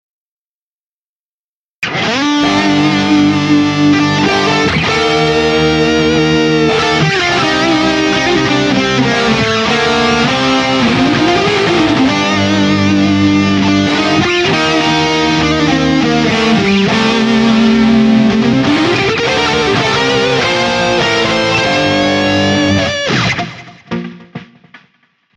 Вниз  Играем на гитаре
solo.mp3